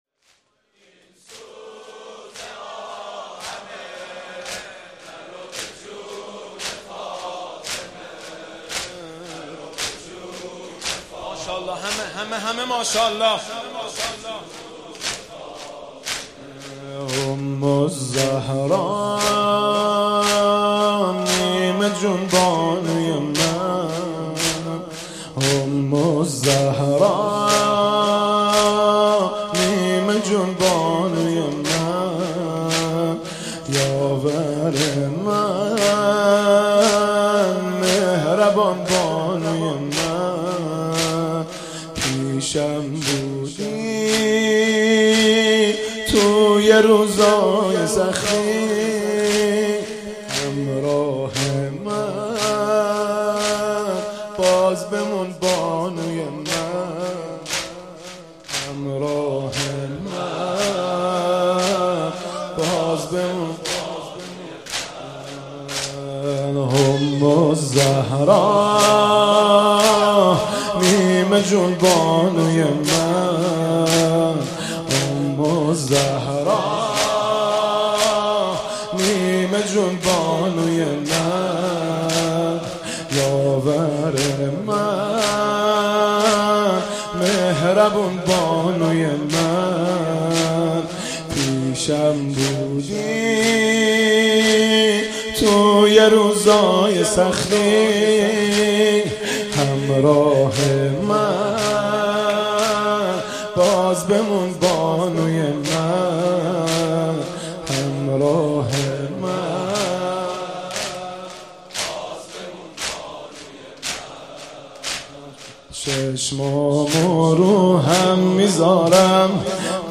مداحی جدید حاج مهدی رسولی شب یازدهم رمضان شام وفات حضرت خدیجه کبری (س) هیات ثارالله زنجان پنجشنبه 26 اردیبهشت 1398